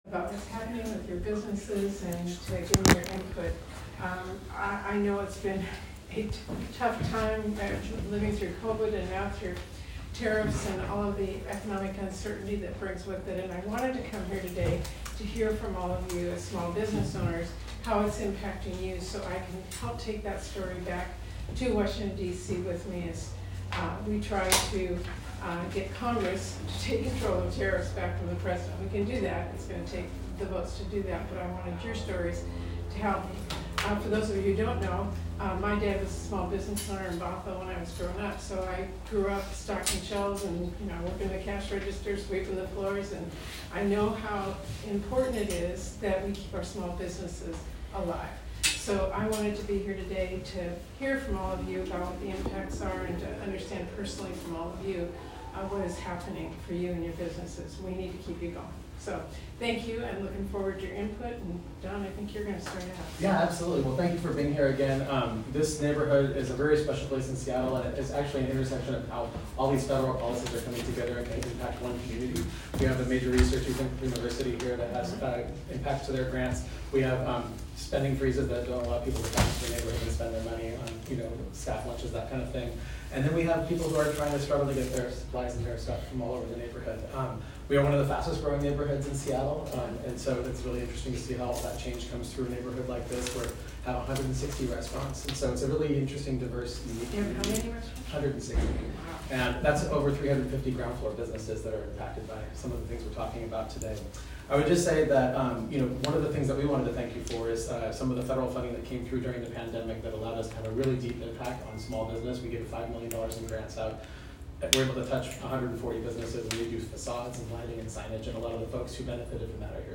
Seattle, WA— Today, U.S. Senator Patty Murray (D-WA), Vice Chair of the Senate Appropriations Committee, met with small business owners in Seattle’s University District to hear how Trump’s chaotic trade war is impacting them.
Cafe-Allegro.m4a